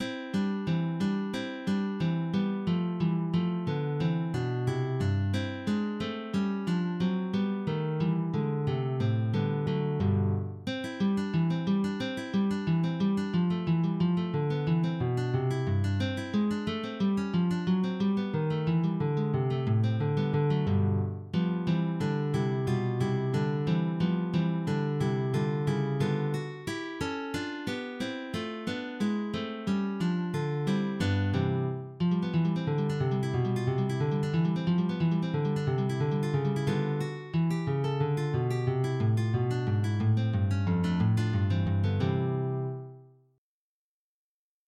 op 130 – n° 17 – Allegretto**